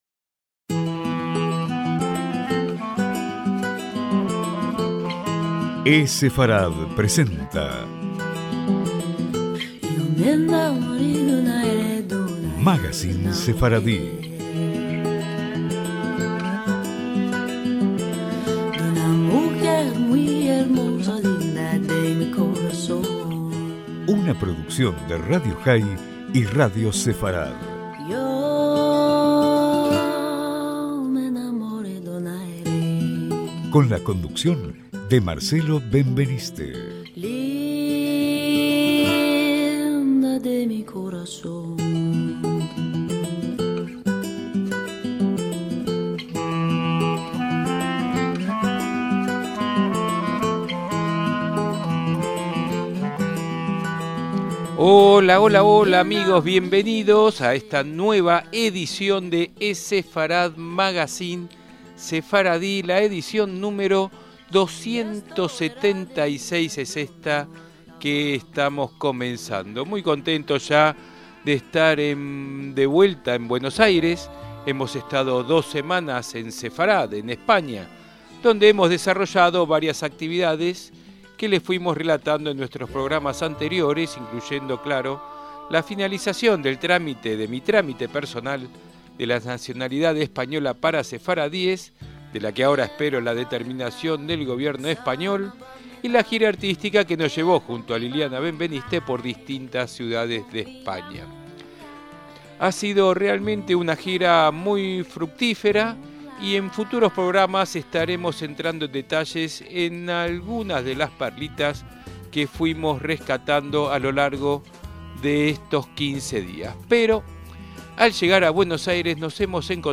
Dos personalidades que son ejemplo de compromiso comunitario y dedicación a la causa. La música a lo largo de todo el programa es del cantor